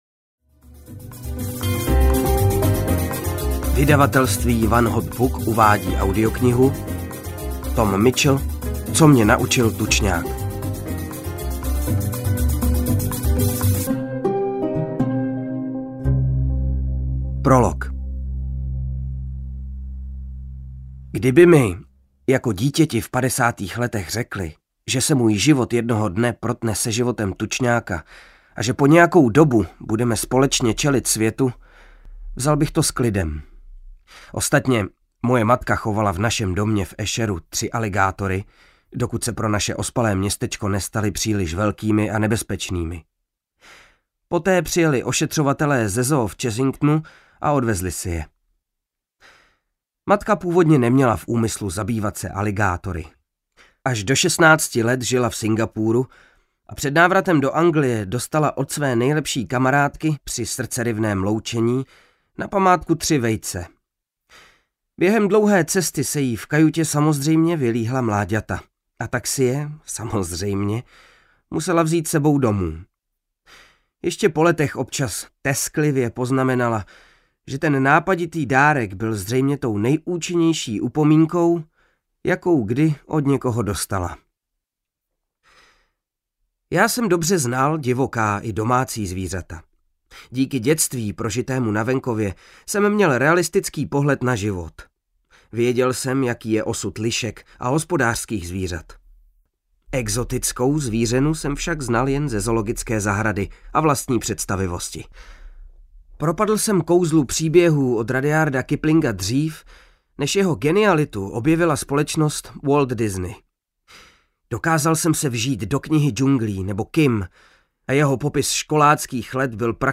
Co mě naučil tučňák audiokniha
Ukázka z knihy
• InterpretKryštof Hádek